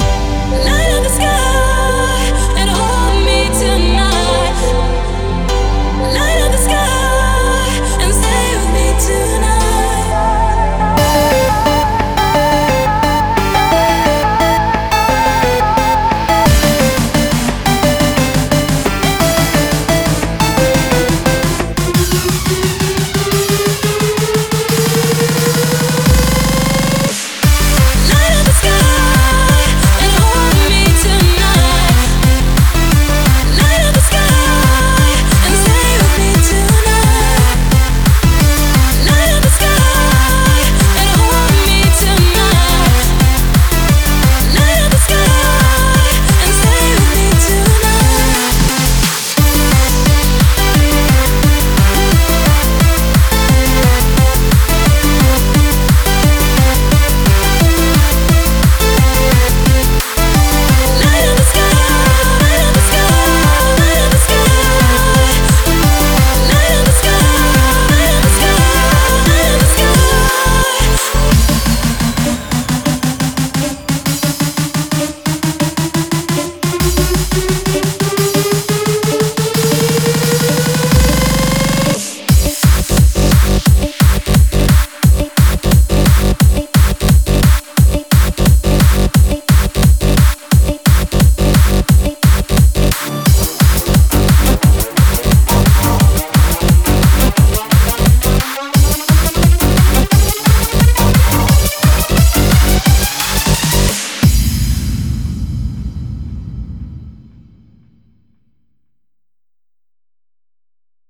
BPM175